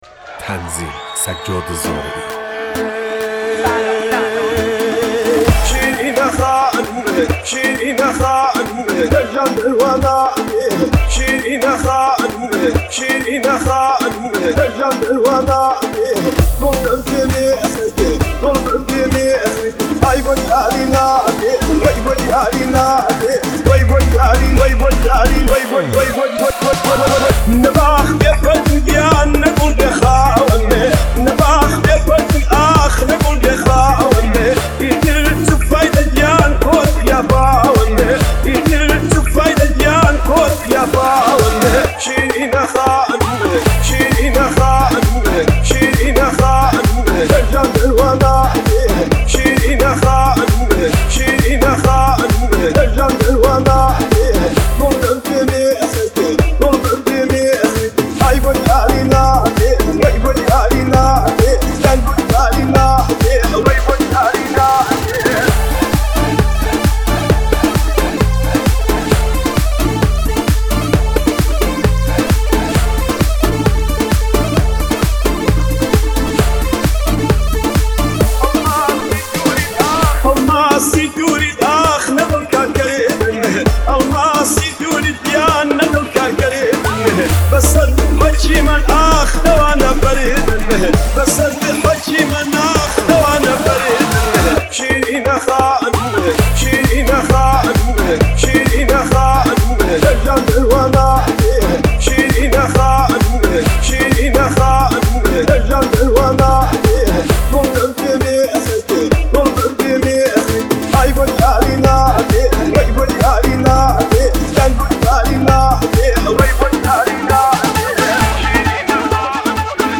آهنگ کردی شاد آهنگ های پرطرفدار کردی
ریمیکس آهنگ کردی شاد